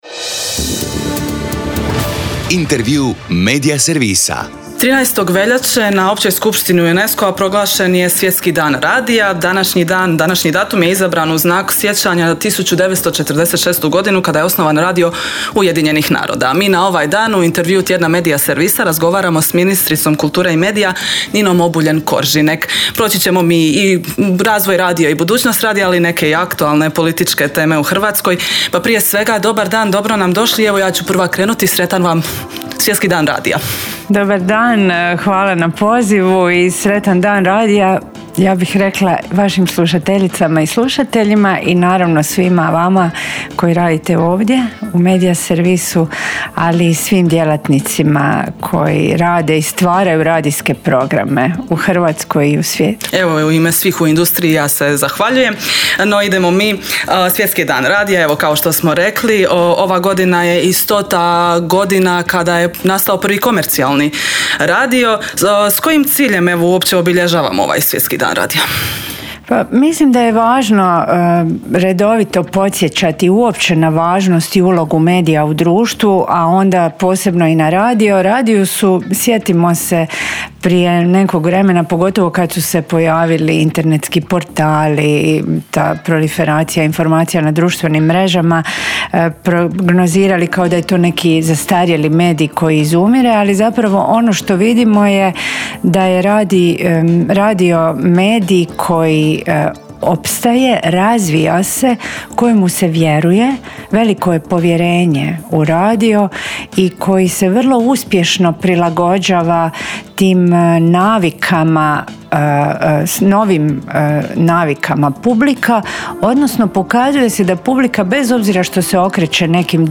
ZAGREB - Povodom Svjetskog dana radija u Intervjuu tjedna Media servisa gostovala je ministrica kulture i medija Nina Obuljen Koržinek koja se, osim r...